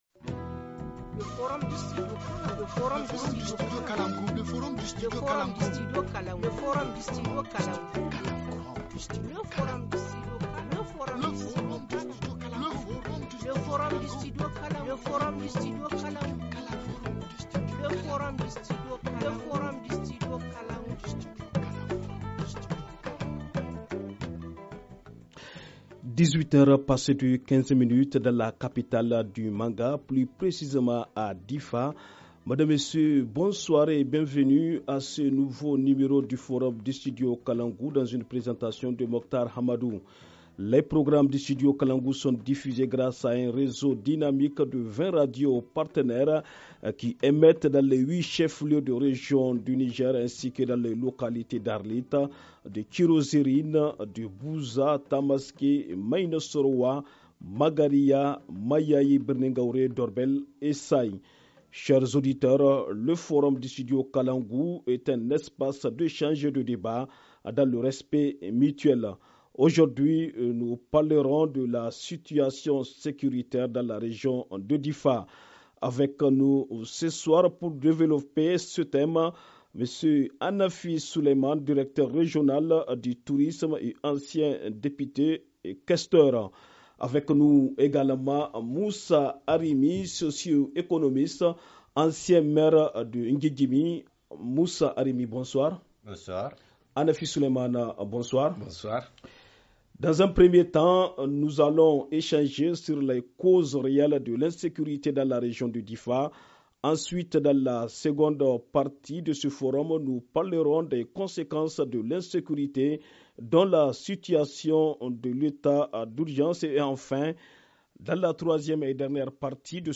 Invités : – M. ANAFFI Souleimane, Directeur régional du Tourisme et ancien député-questeur – M. Moussa Arimi, socioéconomiste, ancien Maire de N’guiguimi FR Forum en […]